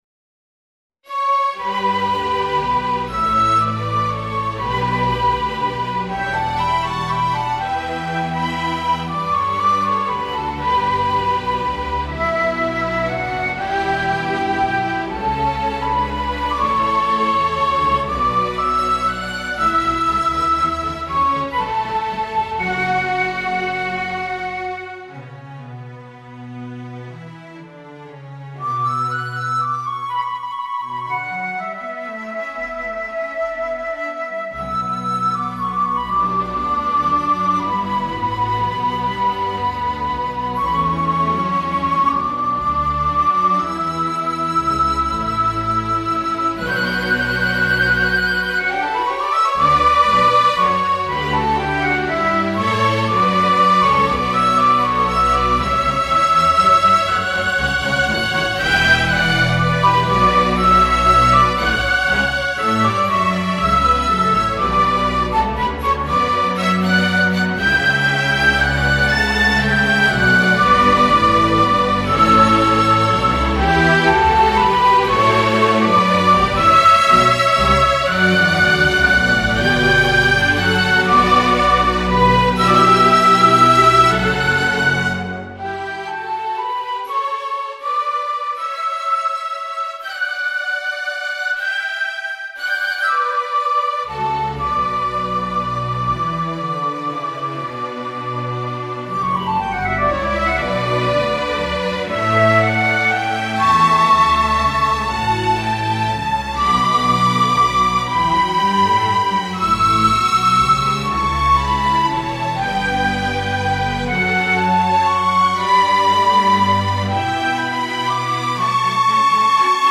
Mock-ups generated by NotePerformer can be heard below (to listen, click on the white arrowhead on the left of the bar).
A tonal and melodic work of three movements with a playing time of 9 minutes:
fantasia-for-flute-and-strings-iii.mp3